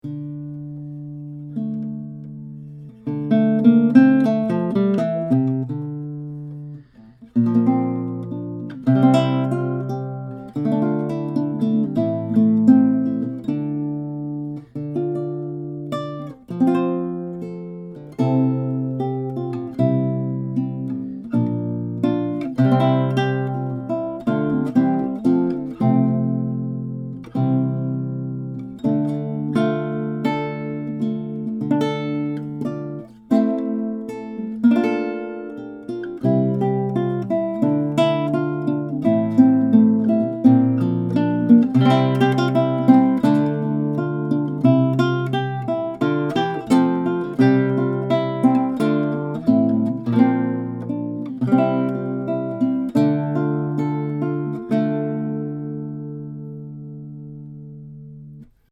Requinto Guitar
* Top: Solid Spruce
* Back/Sides: Indian Rosewood
This guitar is a small-scale requinto, but has a surprisingly big sound, and has all the great qualities of a concert Kohno: excellent resonance, great power and sustain, perfect intonation, and has very even response across the registers.
These MP3 files have no compression, EQ or reverb -- just straight signal, tracked through a pair of Blue Dragonfly cardioid condenser mics, into a Presonus ADL 600 tube preamp into a Rosetta 200 A/D converter.
6 | Improv